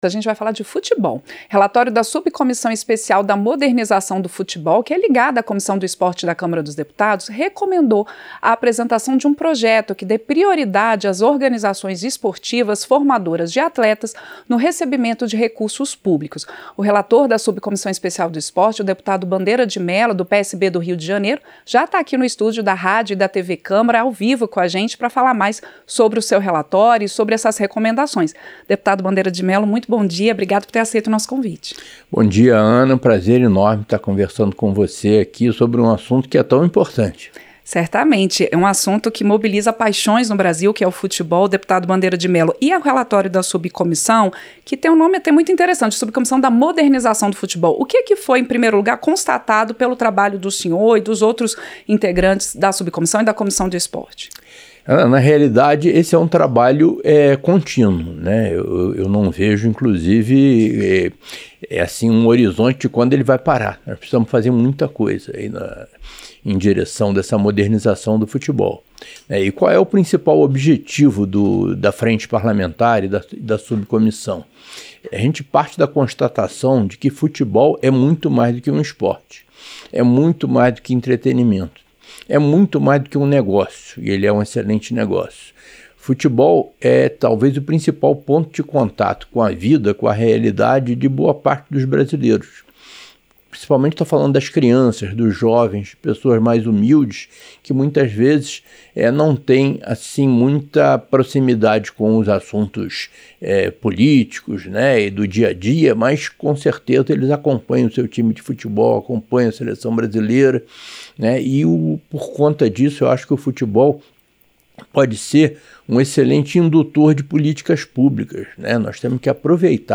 Entrevista - Dep. Bandeira de Mello (PSB-RJ)